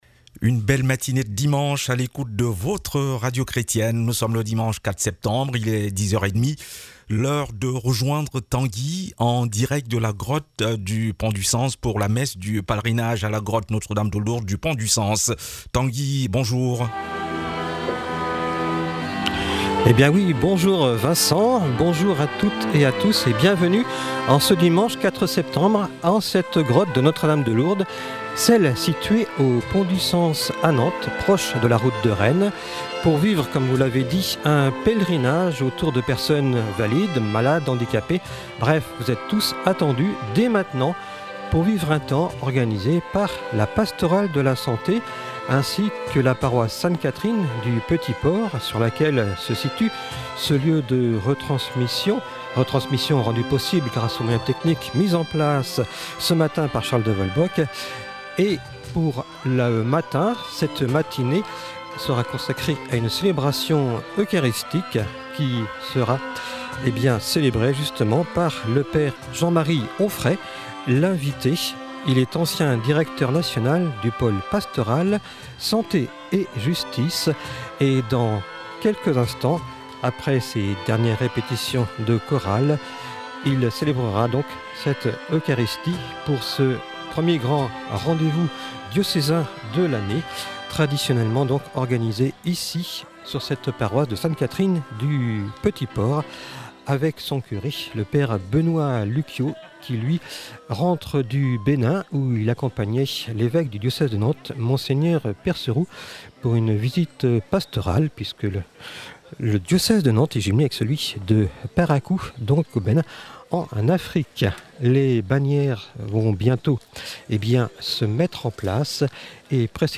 N’oublions pas le travail accompli en amont par les équipes d’art floral, l’installation des chaises et tables (merci la mairie), le nettoyage de la Grotte, l’installation des équipements, le ménage et le balayage pour préparer le site ainsi que l’animation des chants, le groupe musical et la présence de Radio Fidélité.
Voici le podcast de la messe pour réécouter l’homélie :